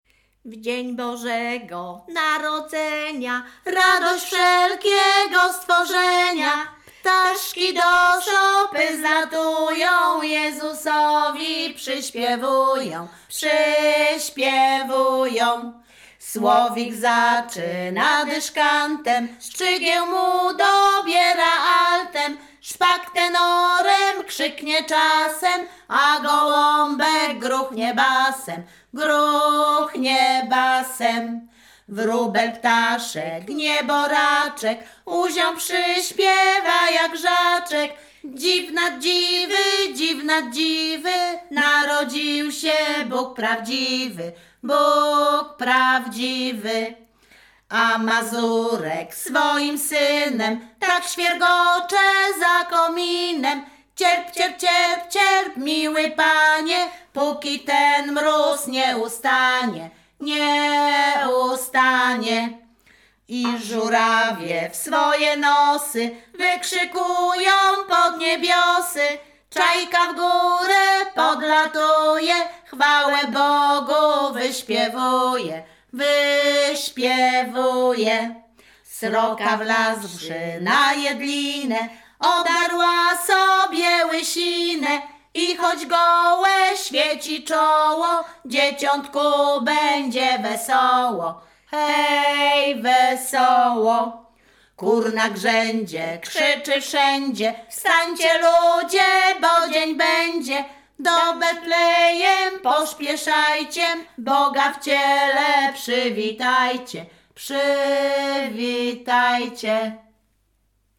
Śpiewaczki z Chojnego
województwo łódzkie, powiat sieradzki, gmina Sieradz, wieś Chojne
Pastorałka
Nagrania w ramach projektu GPCKiE w Plichtowie pt. "Żywa pieśń ludowa. Śpiewy Wzniesień Łódzkich"